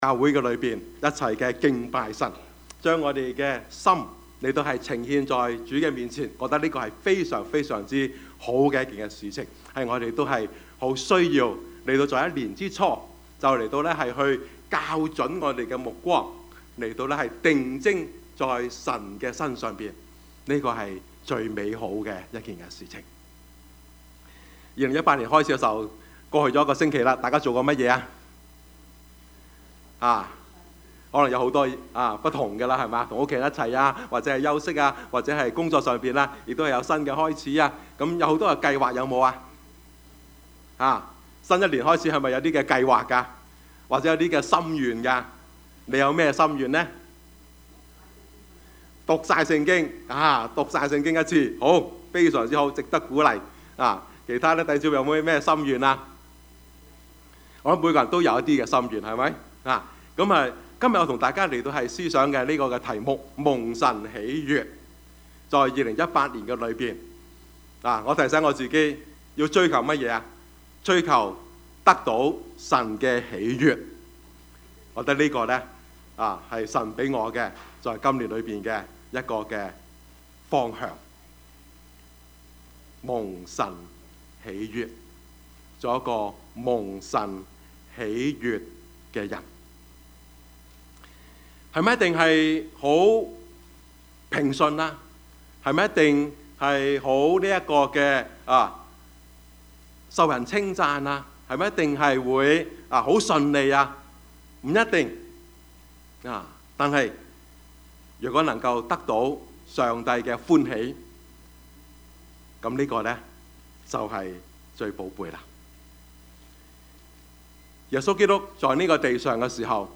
Passage: 馬 可 福 音 1:4-11 Service Type: 主日崇拜
Topics: 主日證道 « 胡適的女人 你來看!